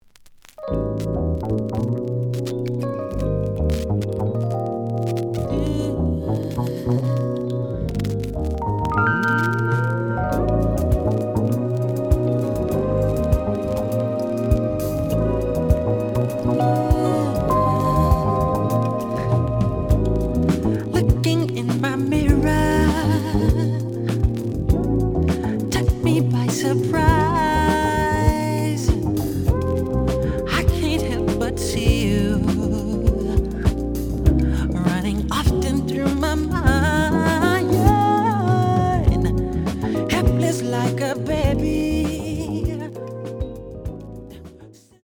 The audio sample is recorded from the actual item.
●Genre: Soul, 80's / 90's Soul
Looks good, but some noise on beginning of B side.